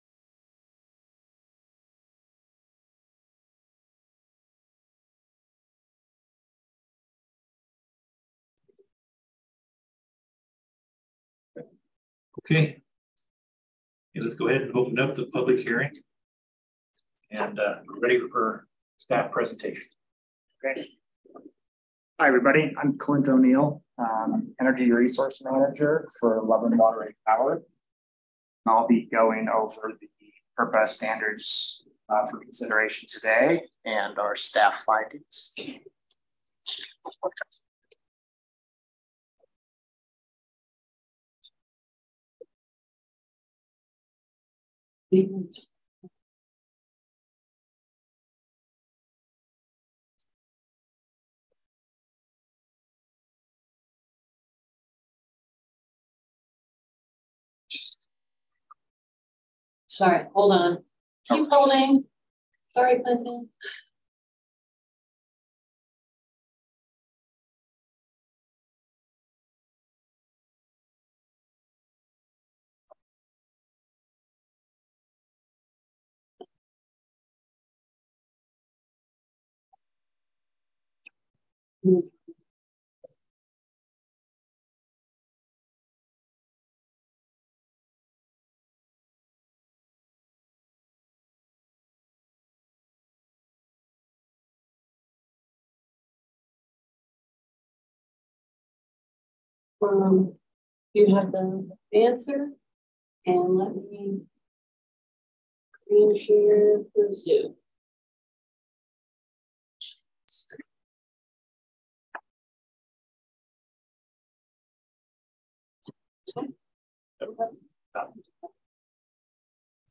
This is a special meeting of the Loveland Utilities Commission.
This meeting will be held on Wednesday, June 21, 2023 at 1:00 p.m. in the Willow Room.